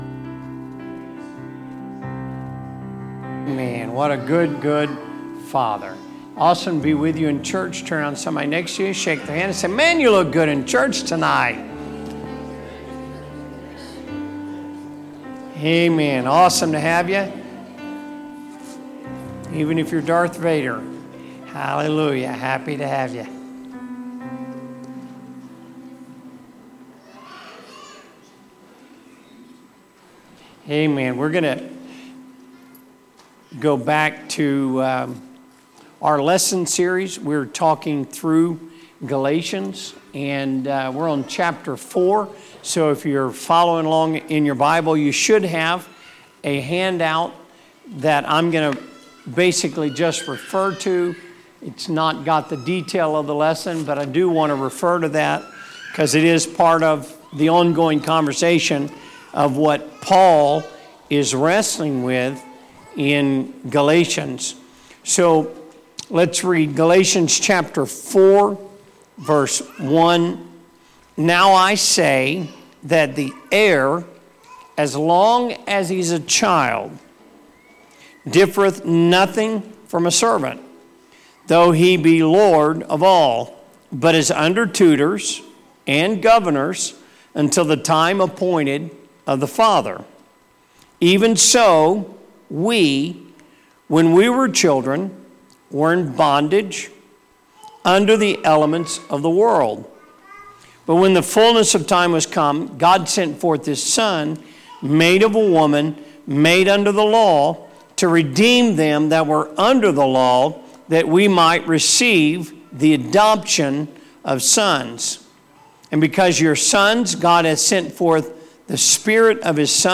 Sermons | Elkhart Life Church
Wednesday Service - Part 7